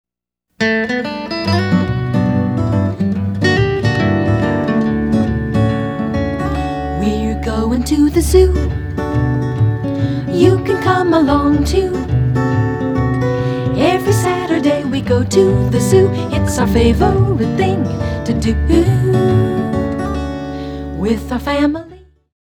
children's music
Original activity songs to teach motor skills and concepts!